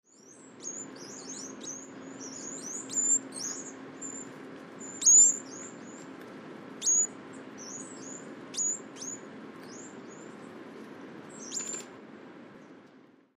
SONS DE AVES
BIS-BIS - Regulus madeirensis